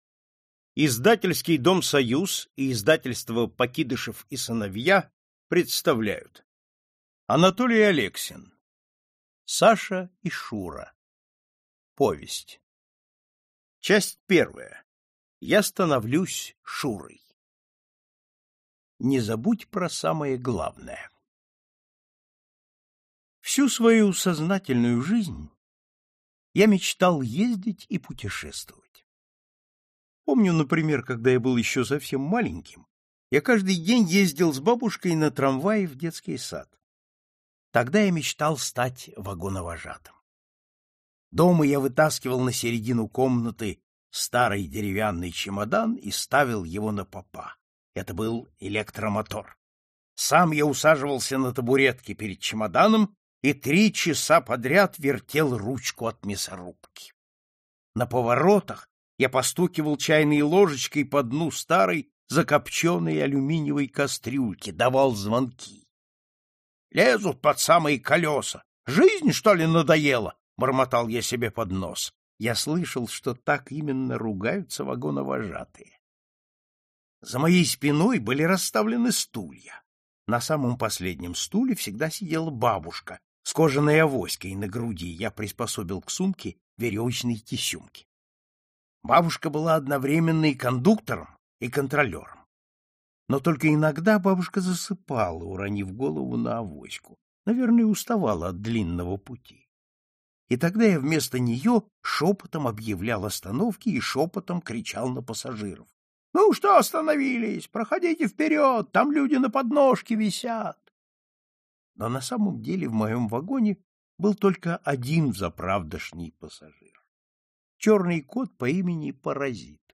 Аудиокнига Саша и Шура | Библиотека аудиокниг